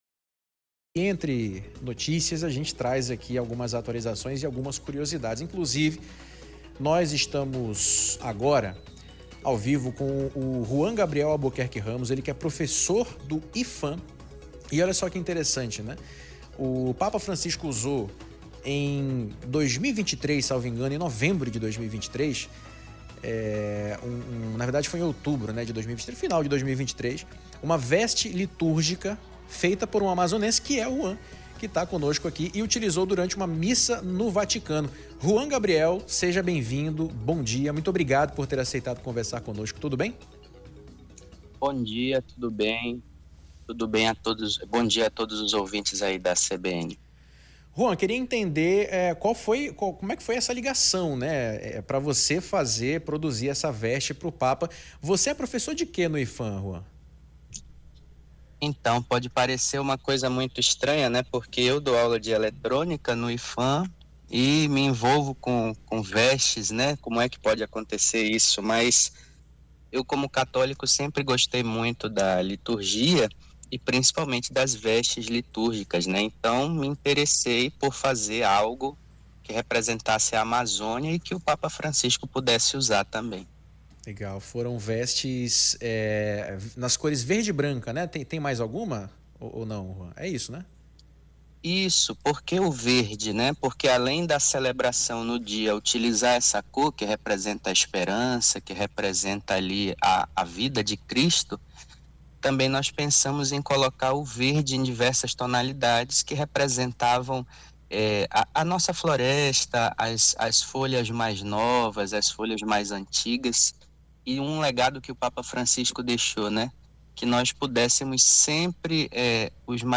ENTREVISTA-ROUPA-PAPA-new.mp3